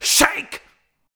SHAKE.wav